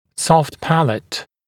[sɔft ‘pælət][софт ‘пэлэт]мягкое нёбо